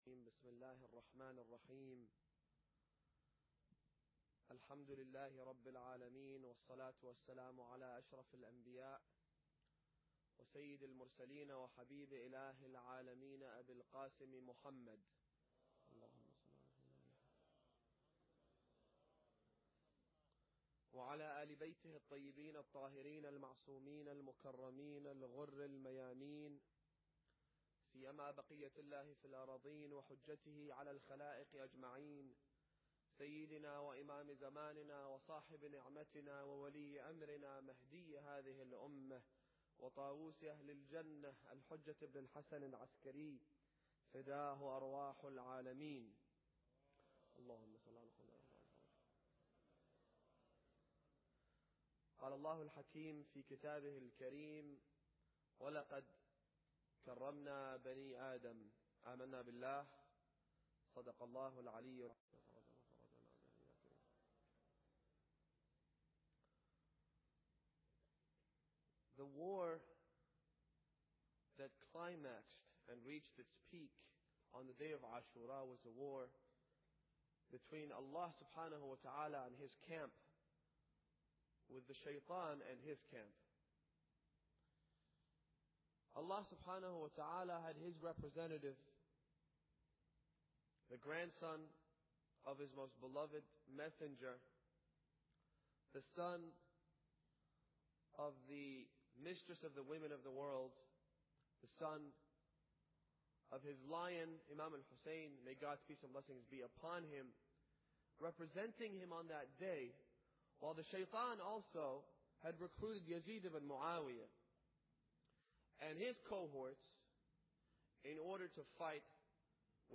Muharram Lecture 5